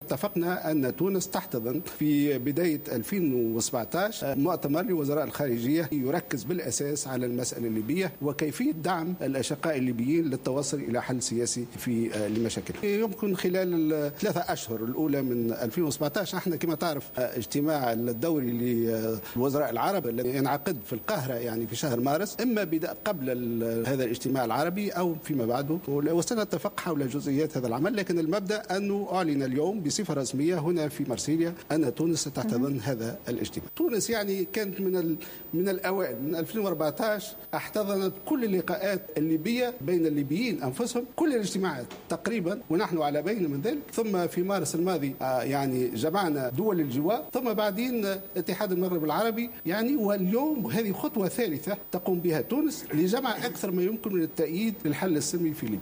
وأضاف في حوار له على قناة فرانس 24 أن هذا القرار اتخذ اليوم في مرسيليا على هامش اجتماع لمجموعة دول غرب المتوسط "5+5"، متوقعا انعقاده قبل شهر مارس من العام المقبل.